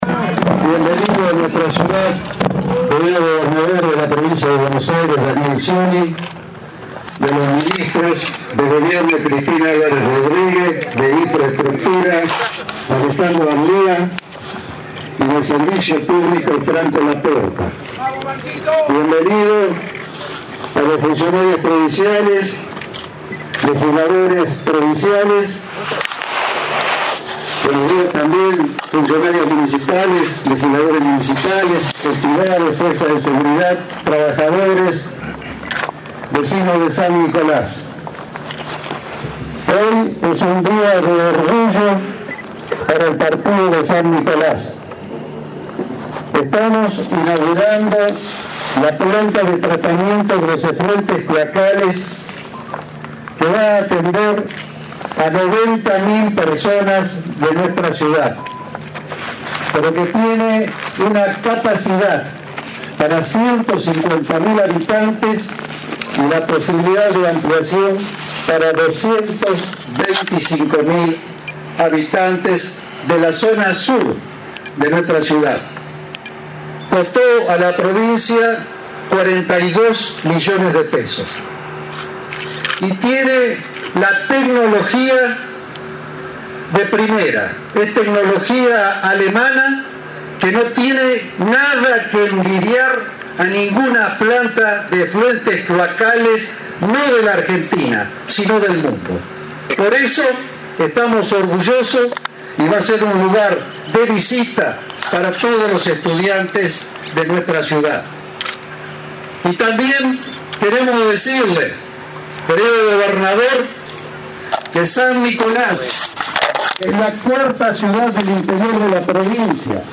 INAUGURACIÓN DE LA PLANTA DE TRATAMIENTO DE EFLUENTES CLOACALES EN ZONA SUR
Int. Municipla de San Nicolás Dr. Ismael Passglia